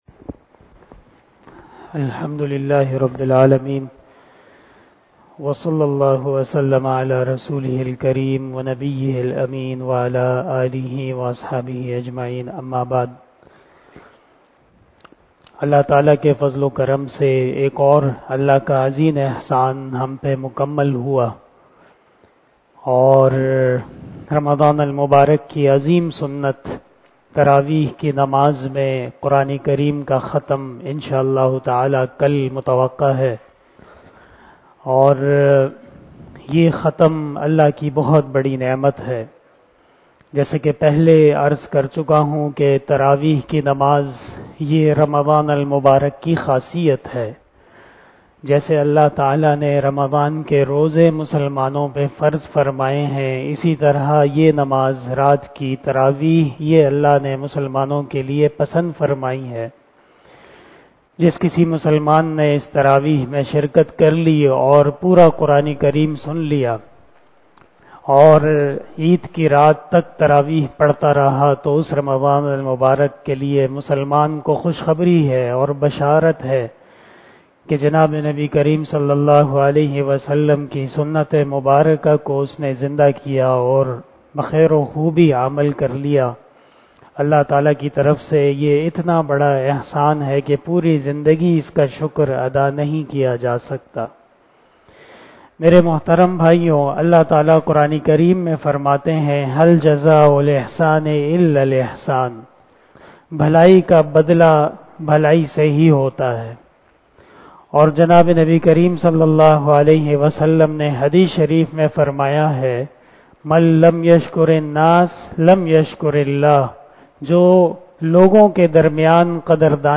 061 After Traveeh Namaz Bayan 27 April 2022 ( 26 Ramadan 1443HJ) Wednesday